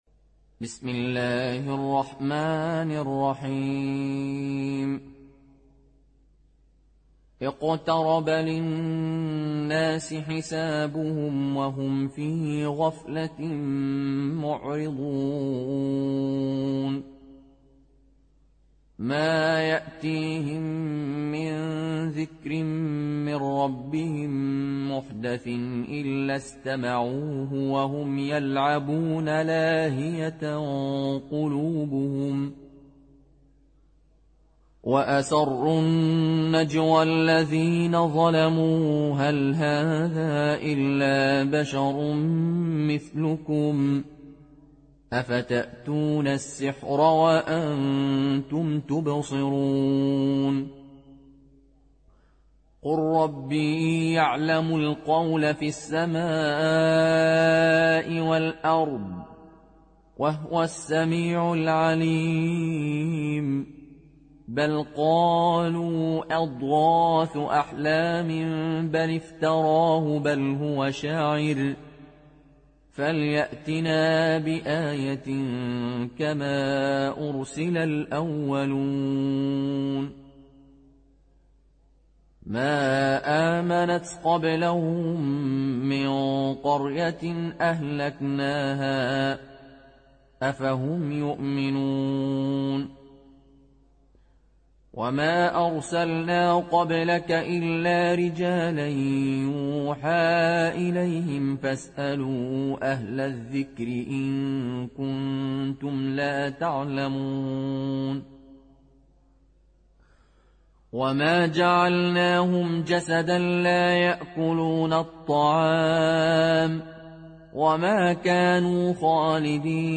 (Riwayat Qaloon)